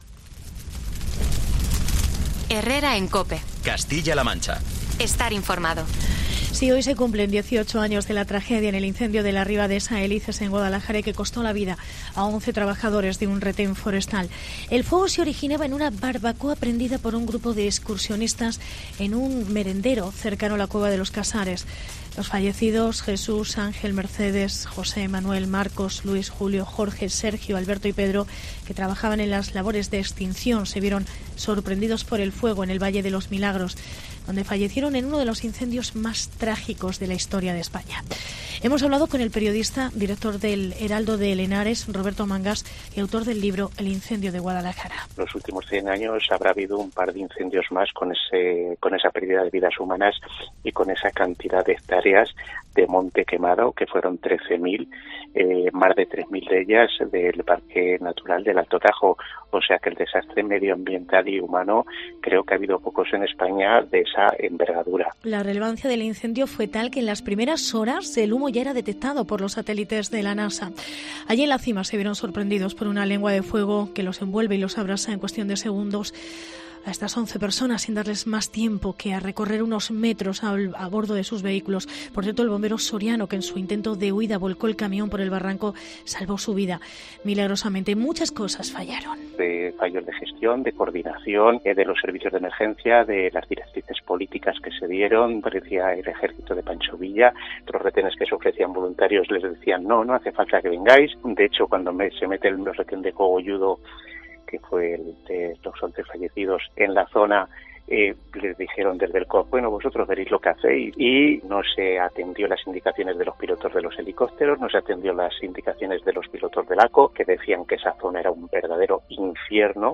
Reportaje 18 aniversario incendio RIba de Saélices